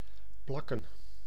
Ääntäminen
US : IPA : [peɪst]